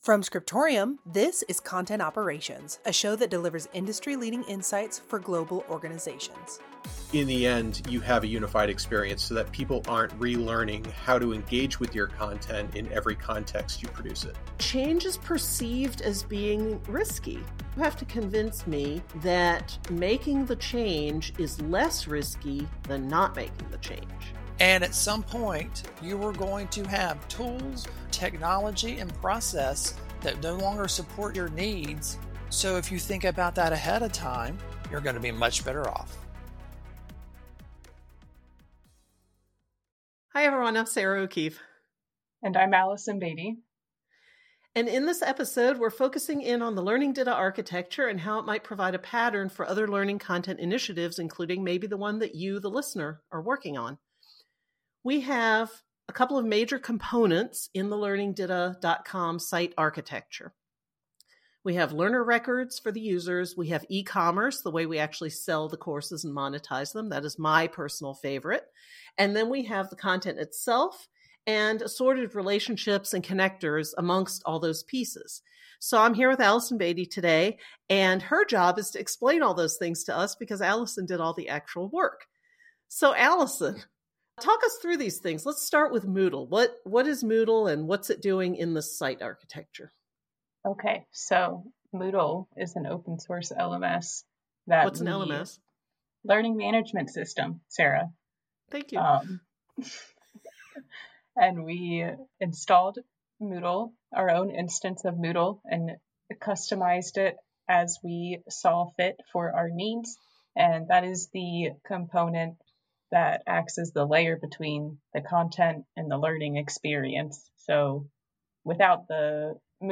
Introduction with ambient background music